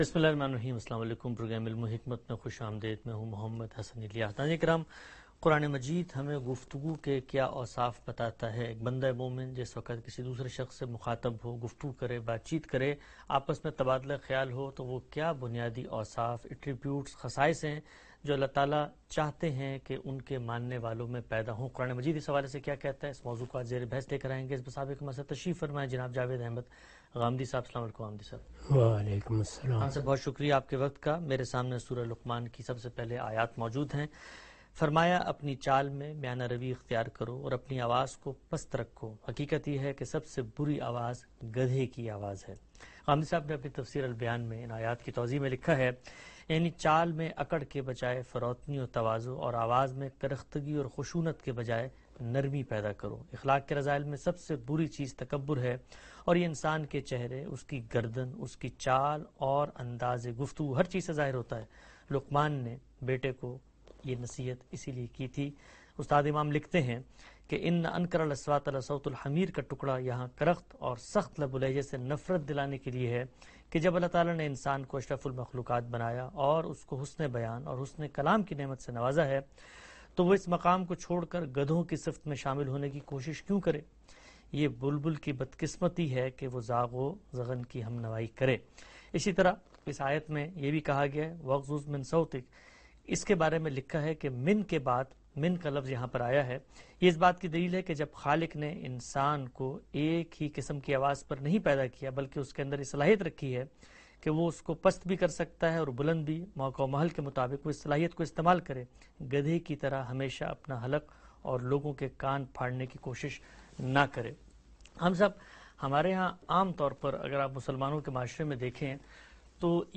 In this program Javed Ahmad Ghamidi answers the questions about "Features of Conversation".